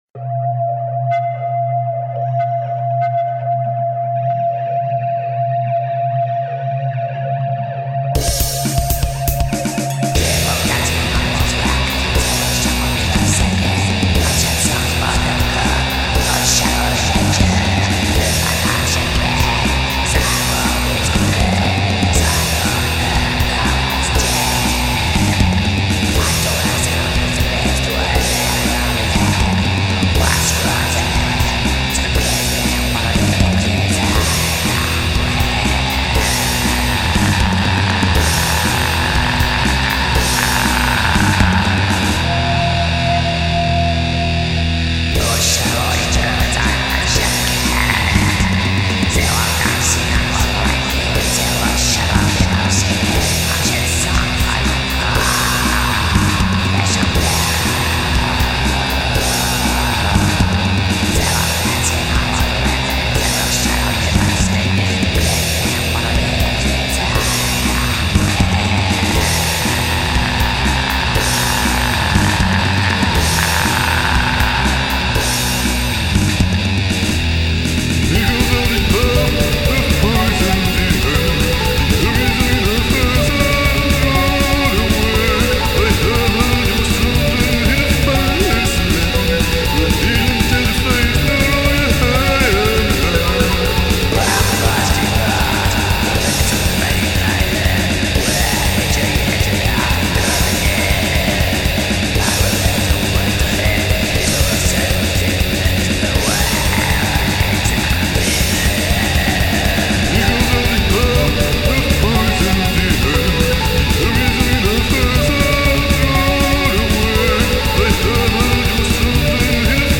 (True Black Métal)